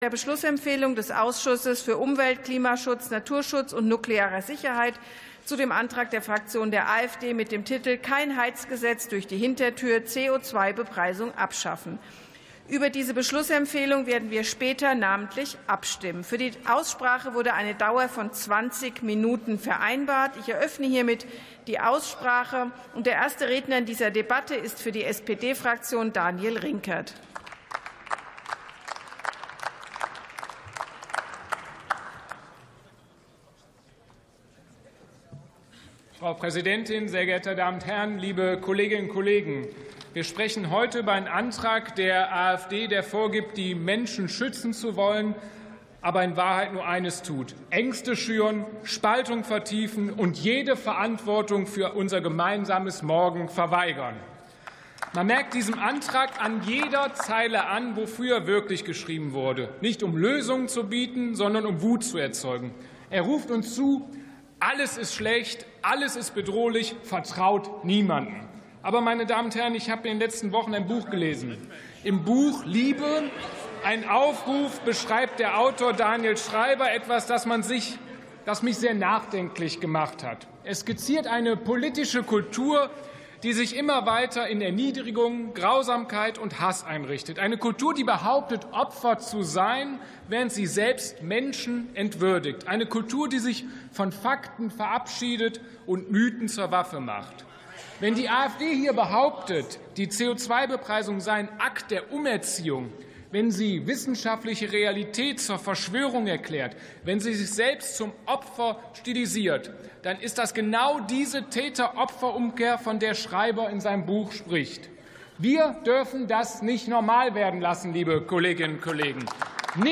Plenarsitzungen - Audio Podcasts